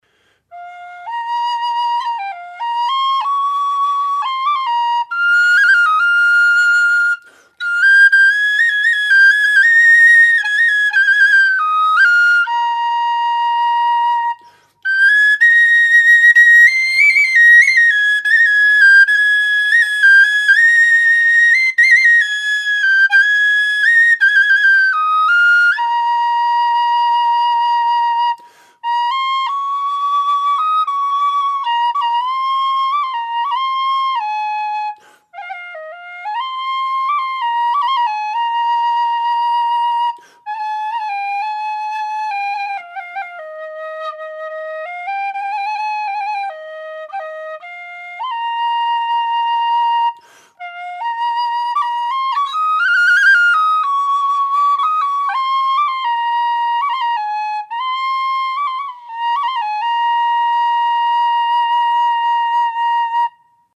Eb whistle - 100 GBP
made out of thin-walled aluminium tubing with 12mm bore
Eb-impro1.mp3